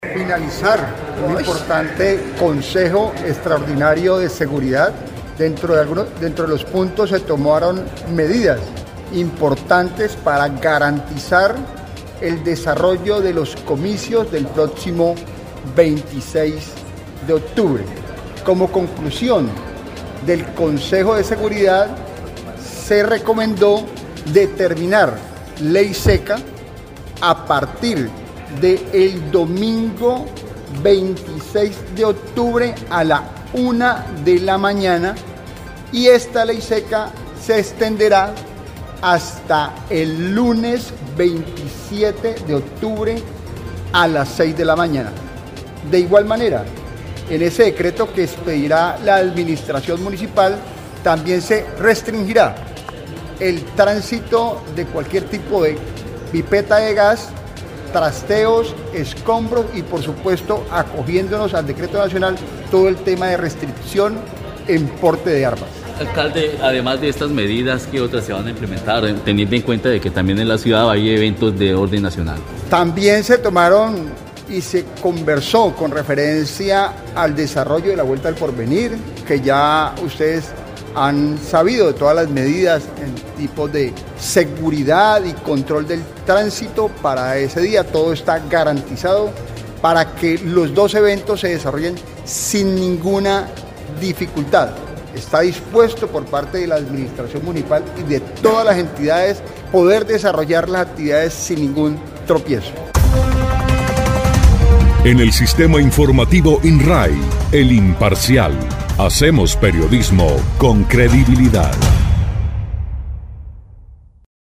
José Delby Vargas Gutiérrez, alcalde encargado y secretario para la gerencia de la gobernabilidad, ordenamiento e institucionalidad, explicó que, la medida se adopta pensando en la preservación de la seguridad durante la jornada electoral de este fin de semana.
02_ALCALDE_JOSE_DELBY_VARGAS_LEY_SECA.mp3